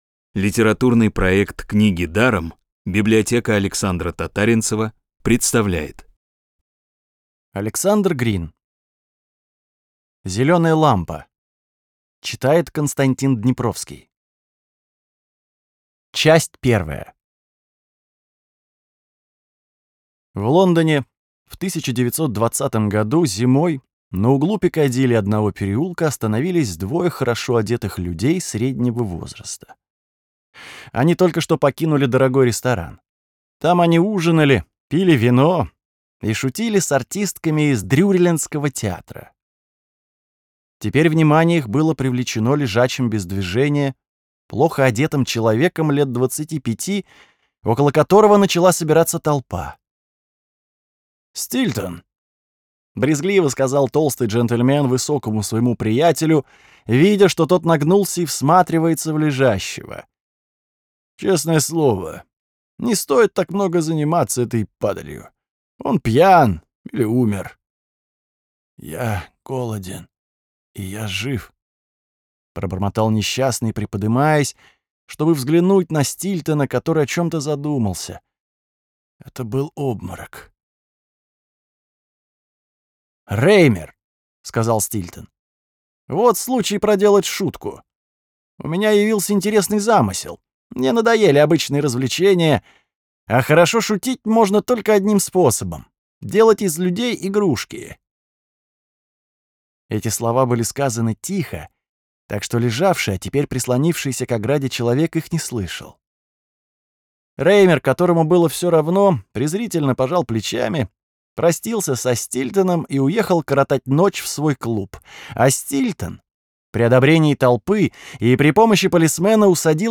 Бесплатная аудиокнига «Зелёная лампа» от Рексквер.
«Книги даром» подготовили для вас аудиоверсию рассказа «Зелёная лампа». А. С. Грин - Зелёная лампа.